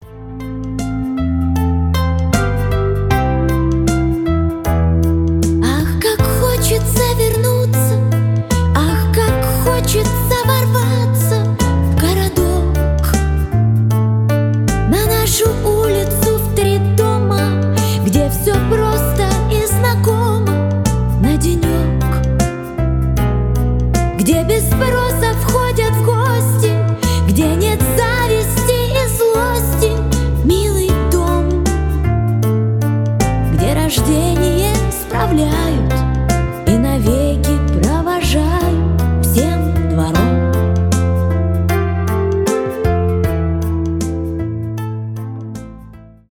грустные
душевные , ретро